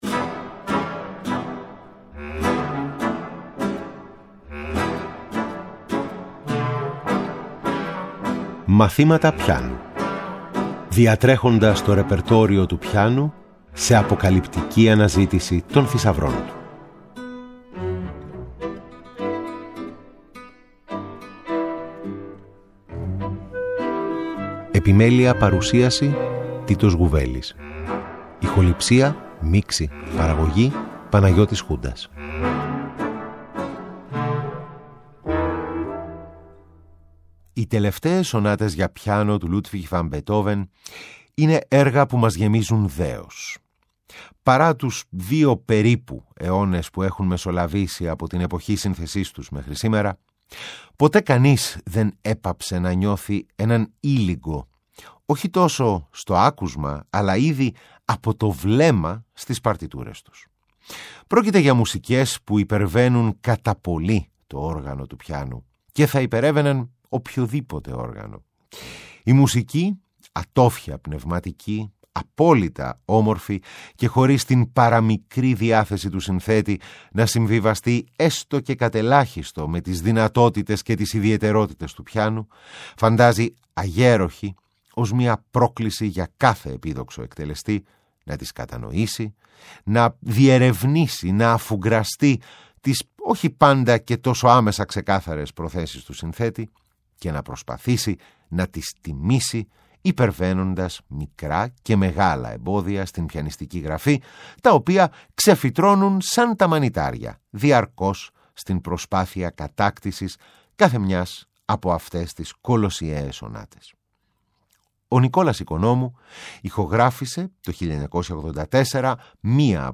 Η δεξιοτεχνική πλευρά του θυελλώδους πιανίστα Νικόλα Οικονόμου, που μπορούσε να αφήσει άφωνο το κοινό με την ταχύτητα και την ακρίβεια του παιξίματός του, αλλά πάνω απ’ όλα με την κατακλυσμιαία ενέργεια που διοχέτευε σε κάθε φράση της μουσικής που ερμήνευε.
Εγχείρημα το οποίο ενισχύει η ενίοτε ζωντανή ερμηνεία χαρακτηριστικών αποσπασμάτων κατά τη διάρκεια της εκπομπής.